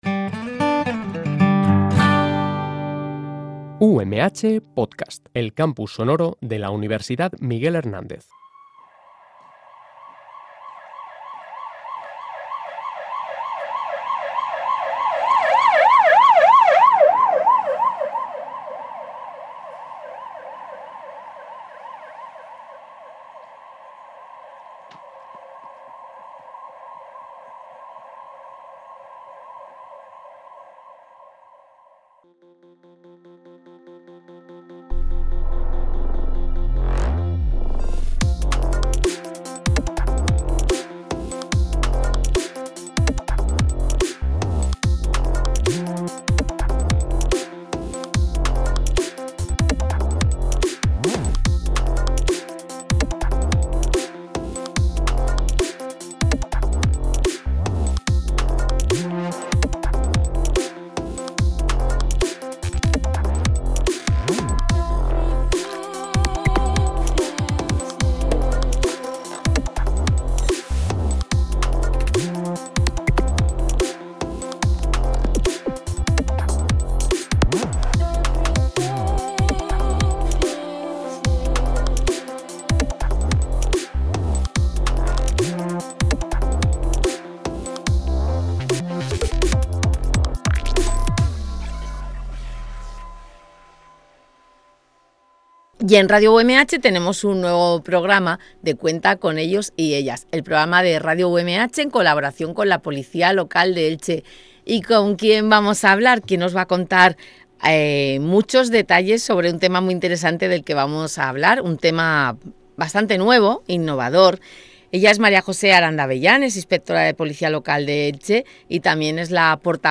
En Radio UMH emitimos una temporada más el espacio radiofónico «Cuenta con ellos y ellas», un programa en colaboración con la Policía Local de Elche en el que vamos a tratar muchos aspectos relacionados con las tareas que realizan en su día a día y temas de muchísimo interés para la ciudadanía.